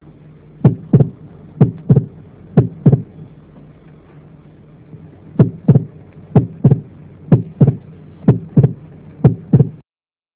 This Page contains some of the sounds of pathological Mitral and Aortic Valve Lesions.
Mitral Opening Snap